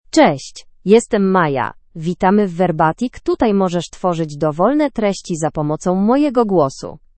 Maya — Female Polish (Poland) AI Voice | TTS, Voice Cloning & Video | Verbatik AI
Maya is a female AI voice for Polish (Poland).
Voice sample
Listen to Maya's female Polish voice.
Female